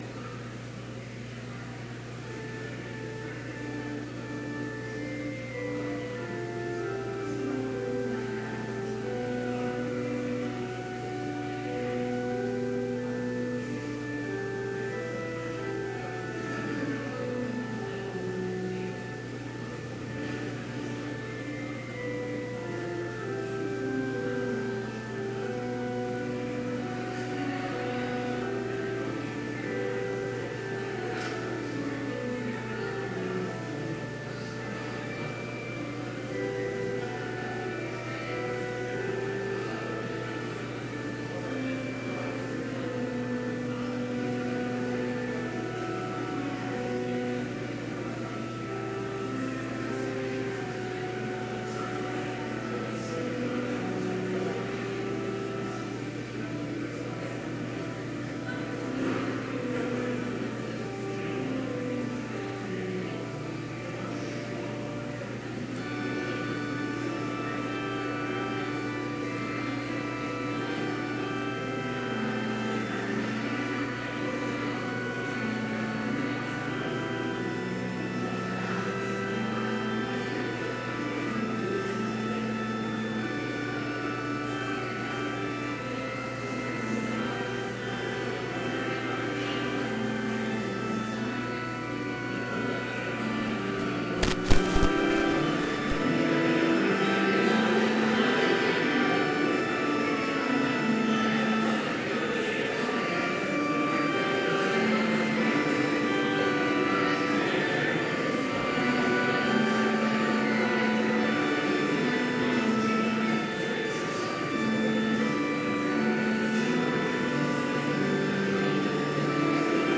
Complete service audio for Chapel - October 11, 2017